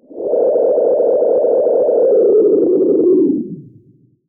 Filtered Feedback 05.wav